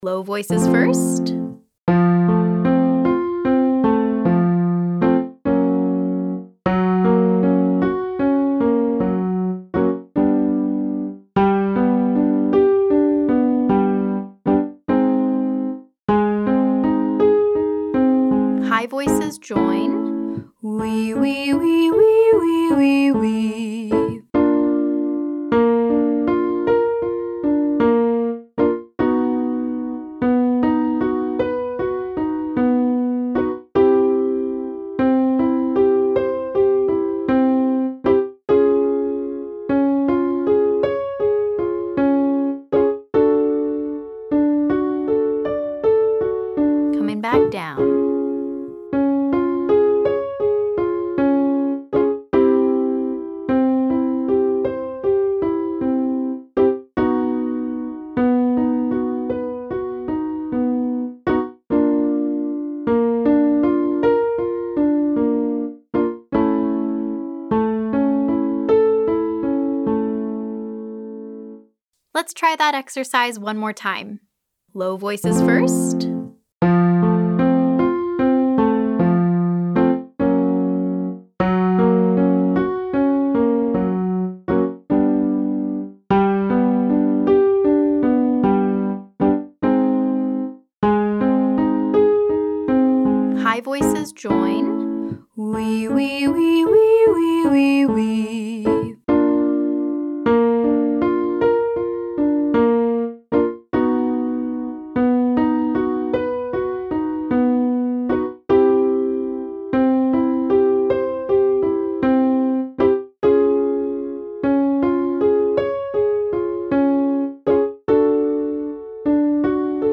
Exercises for day 4: